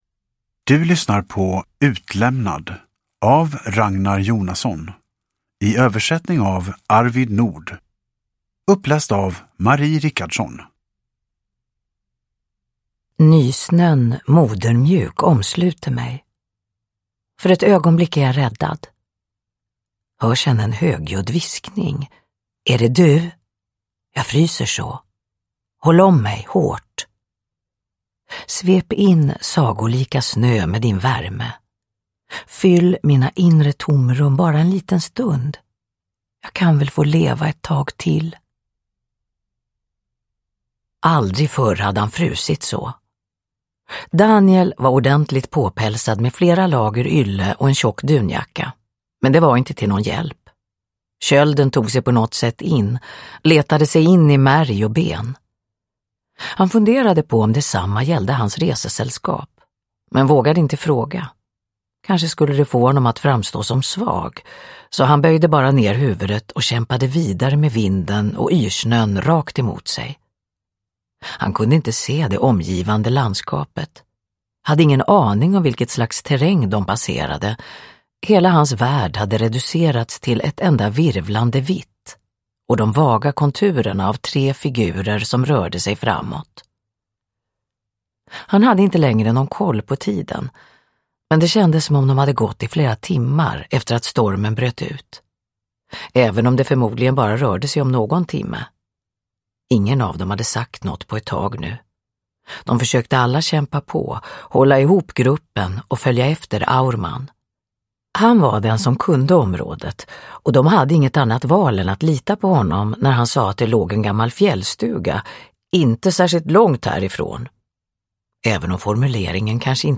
Utlämnad – Ljudbok – Laddas ner
Uppläsare: Marie Richardson